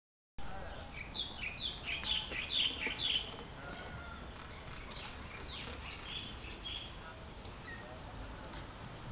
เสียงบรรยากาศ